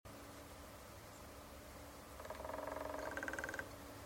CASAL DE BENEDITO DE TESTA sound effects free download
CASAL DE BENEDITO DE TESTA AMARELA.